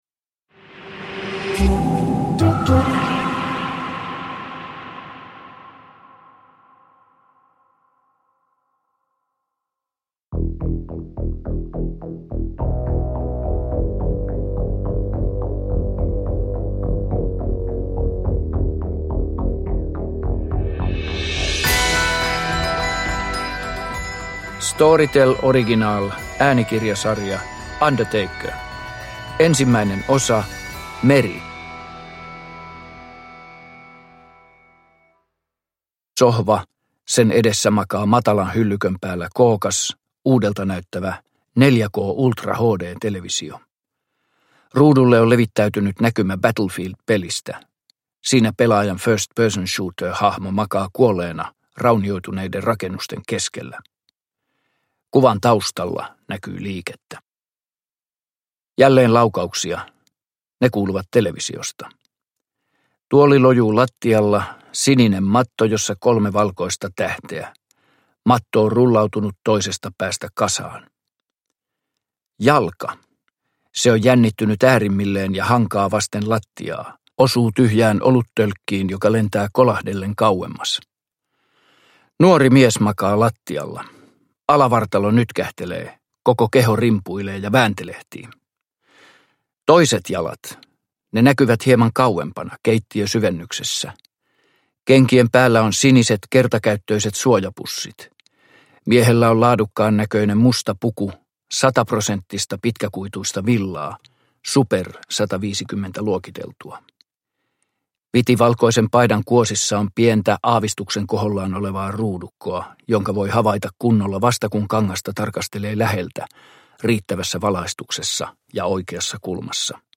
Undertaker 1 - Kuolemantuomio – Ljudbok – Laddas ner
Uppläsare: Pirkka-Pekka Petelius